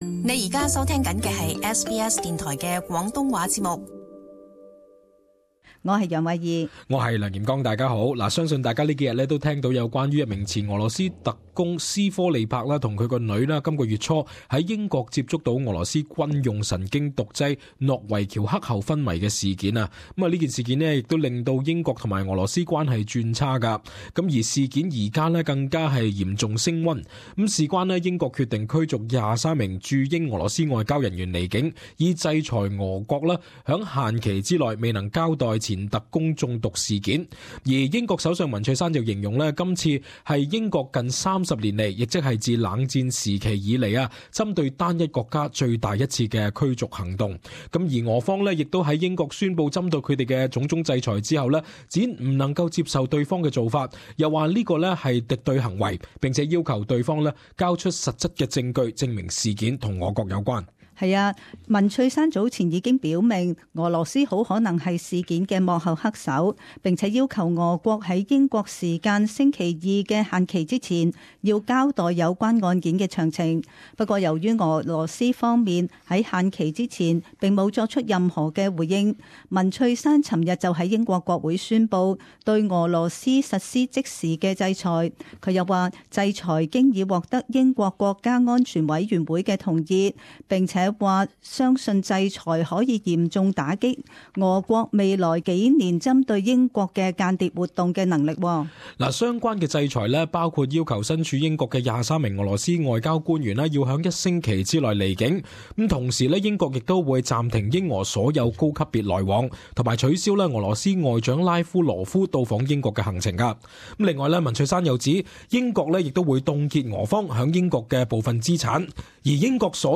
【時事報導】俄羅斯未有回應前特工中毒案 英驅23俄國外交人員